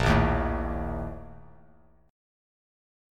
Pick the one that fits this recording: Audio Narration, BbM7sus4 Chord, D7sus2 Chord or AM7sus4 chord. BbM7sus4 Chord